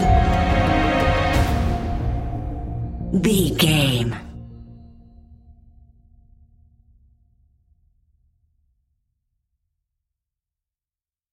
Scary Background Industrial Music Stinger.
Aeolian/Minor
A♭
ominous
eerie
synthesizer
strings
horror music